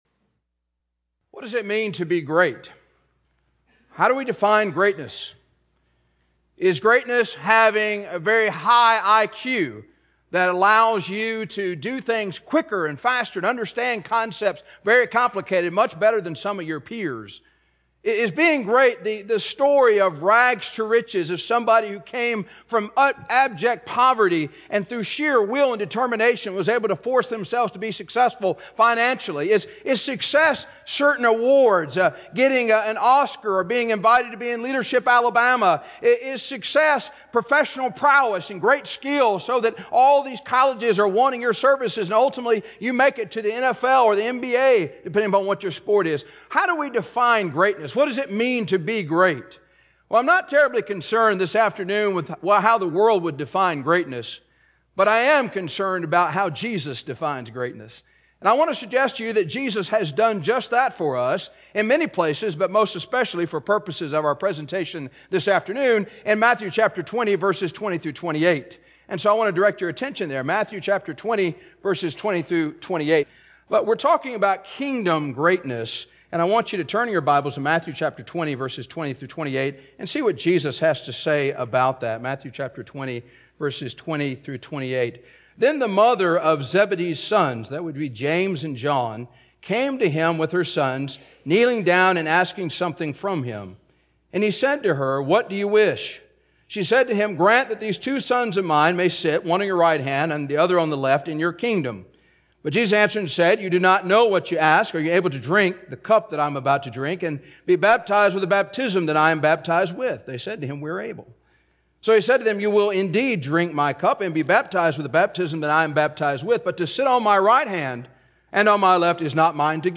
Type: Sermon